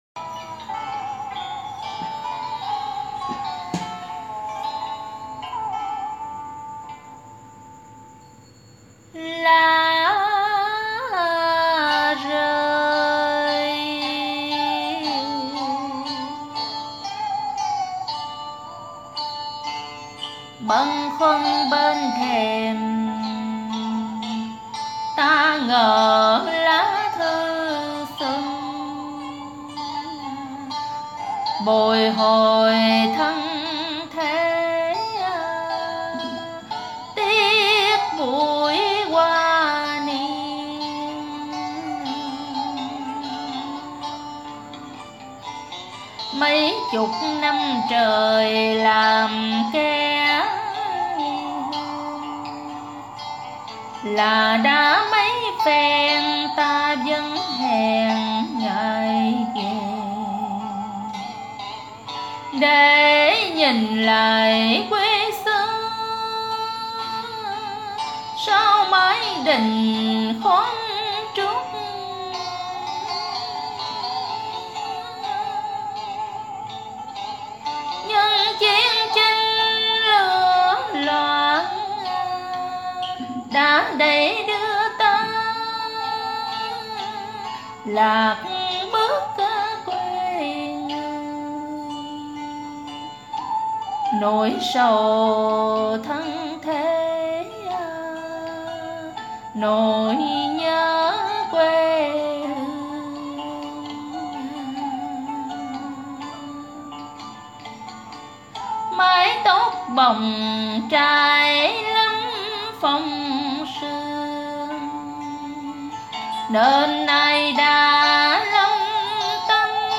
hát theo
điệu Văn Thiên Tường